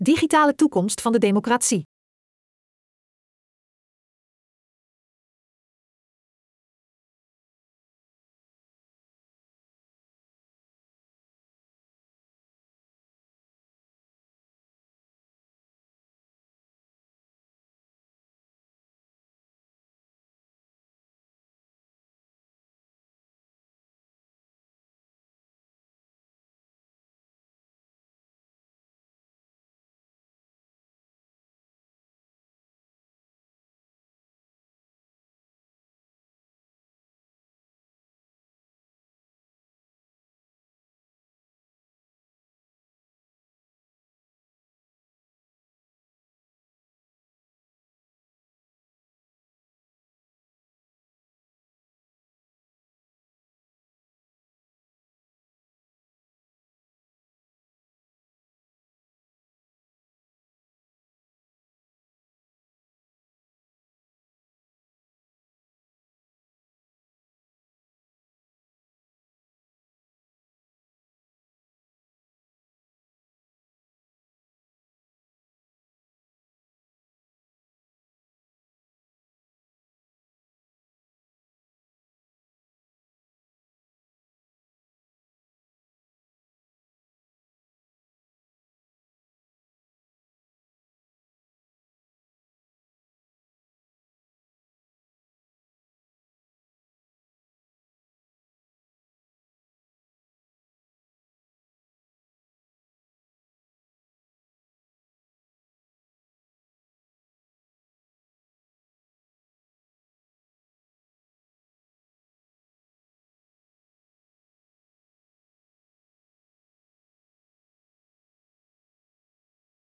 RADIO Webinar: De Digitale Toekomst van de Democratie